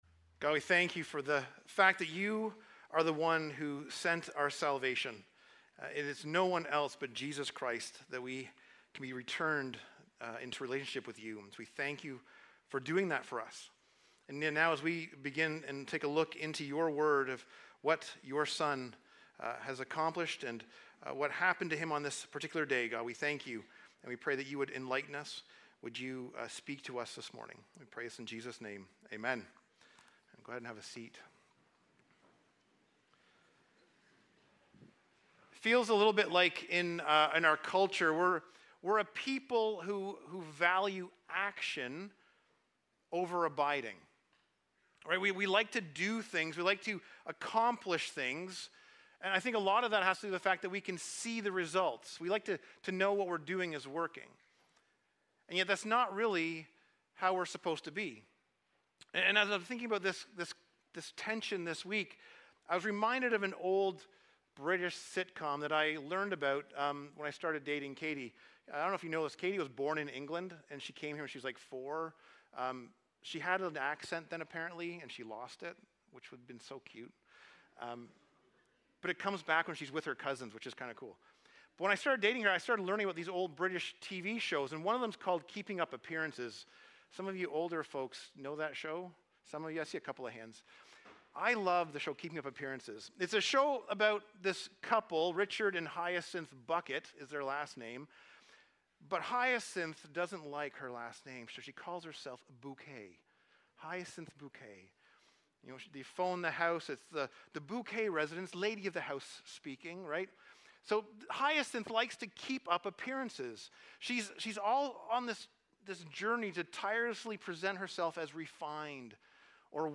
Sermons | James North Baptist Church